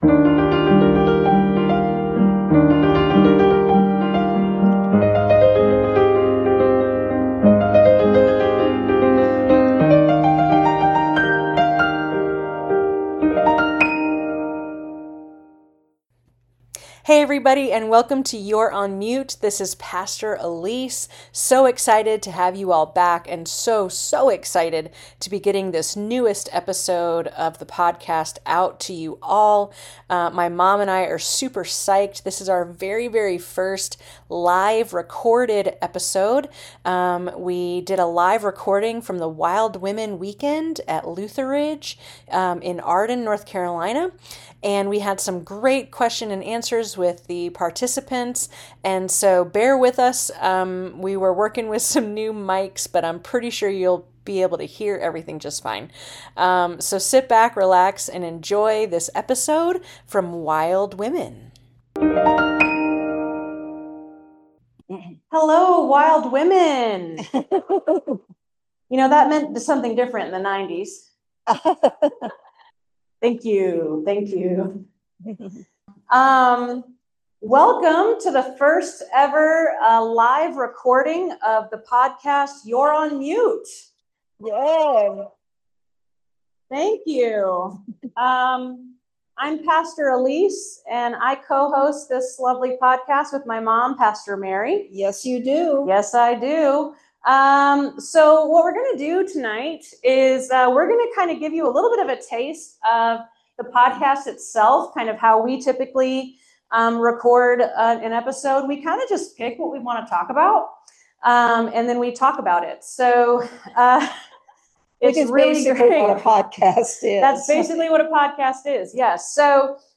For our first ever live recording we are coming at you from the Wild Women Retreat at Camp Lutheridge!